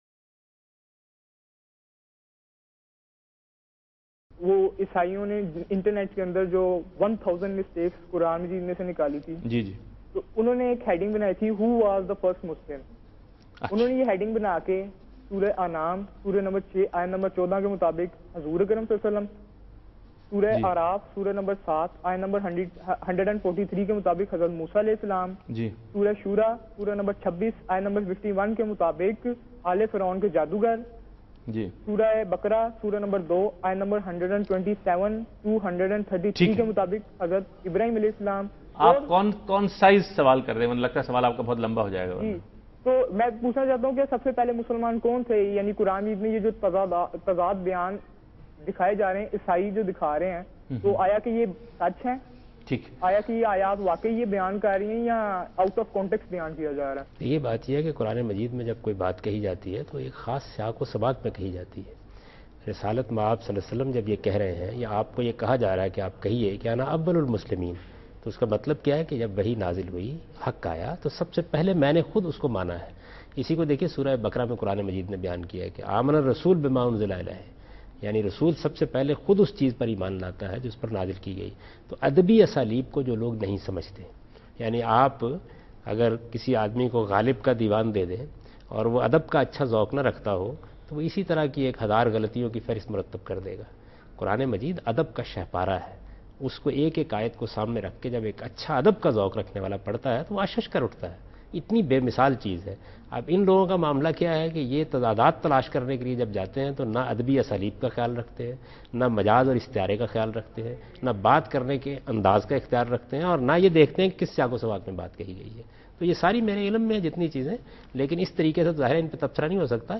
Category: TV Programs / Dunya News / Deen-o-Daanish /
Javed Ahmad Ghamidi answer a question about criticism on Quran by christians.